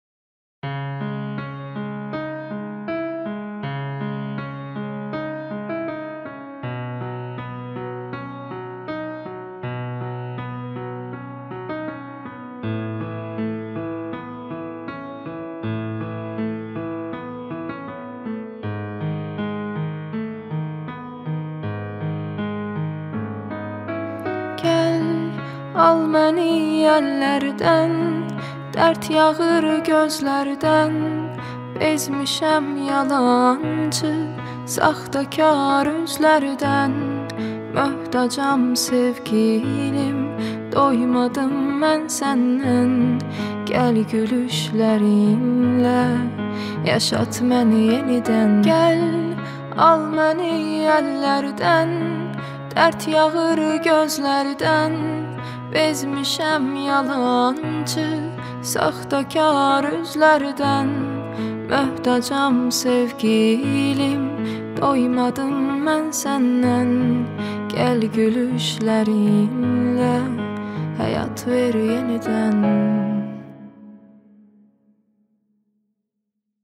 Трек размещён в разделе Турецкая музыка.